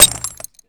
grenade_hit_01.WAV